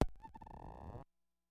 标签： MIDI-速度-32 FSharp4 MIDI音符-67 雅马哈-CS-30L 合成器 单票据 多重采样
声道立体声